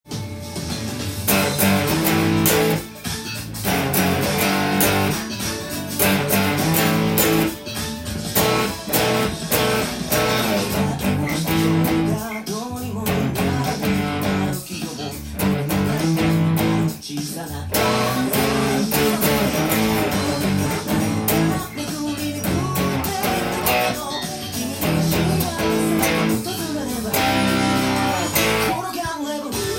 音源にあわせて譜面通り弾いてみました
殆どパワーコードで弾けるようにしていますので
Aメロからブリッジミュートをしながら弾くと